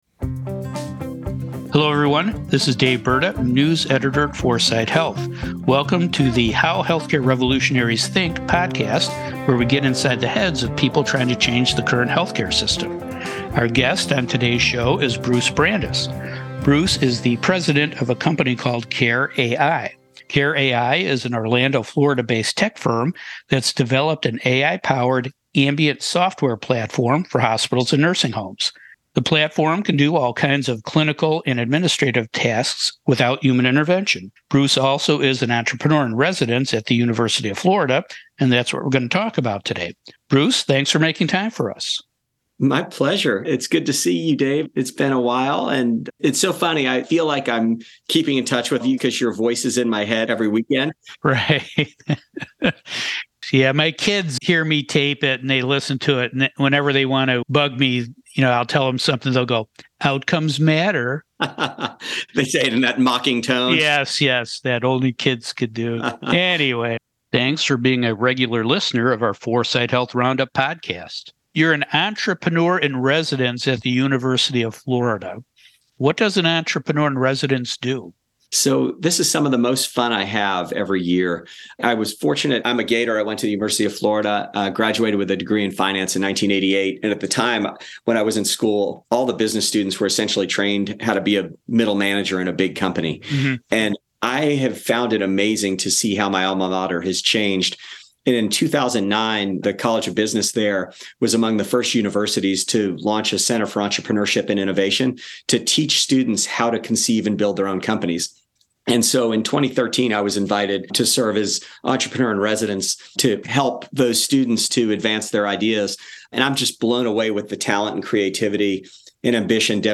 4sight Radio & TV Interviews